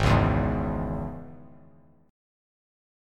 Absus4#5 chord